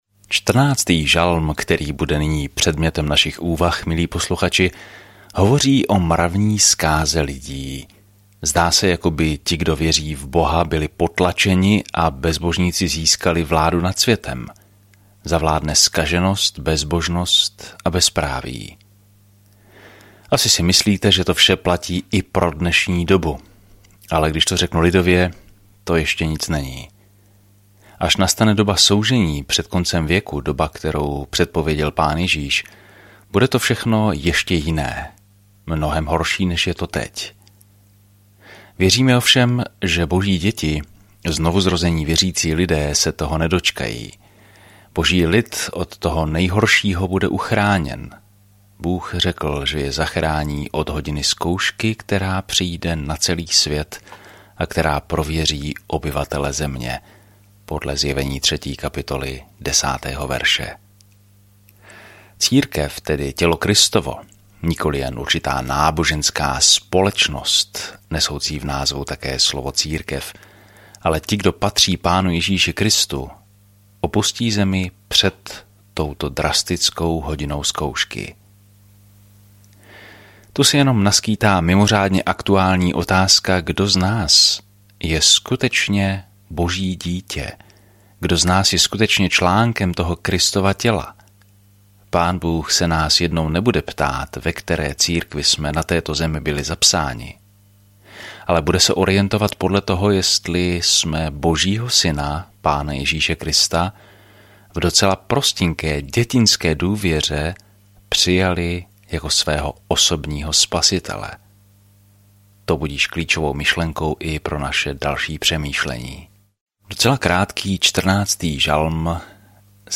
Písmo Žalmy 14 Žalmy 15 Den 9 Začít tento plán Den 11 O tomto plánu Žalmy nám dávají myšlenky a pocity z řady zkušeností s Bohem; pravděpodobně každý z nich původně zhudebnil. Denně procházejte žalmy, poslouchejte audiostudii a čtěte vybrané verše z Božího slova.